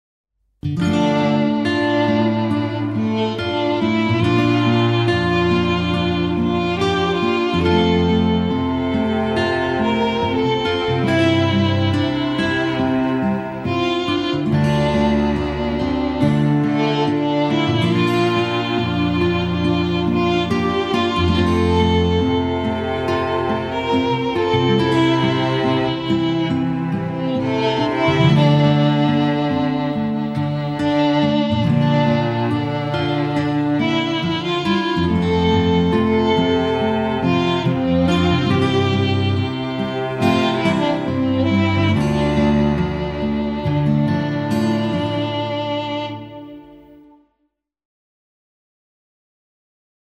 intimiste - folk - melodieux - romantique - aerien